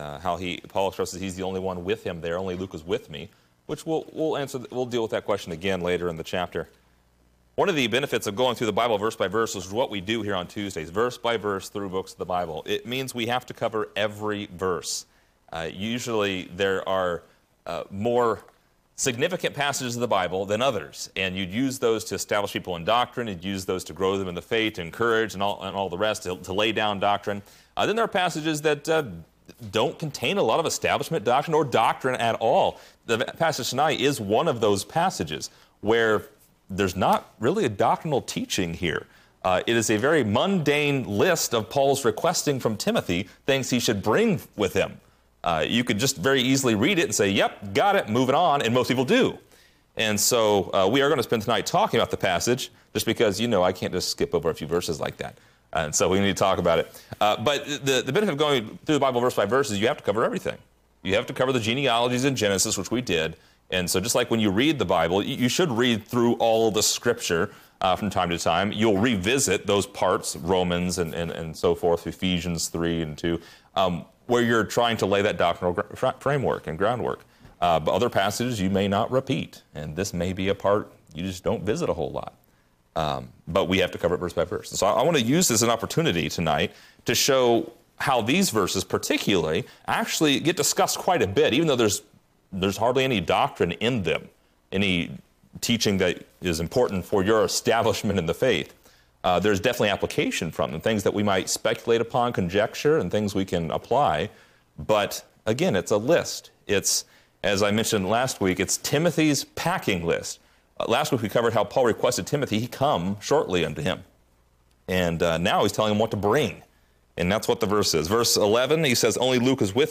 Description: This lesson is part 48 in a verse by verse study through 2 Timothy titled: Bring with Thee.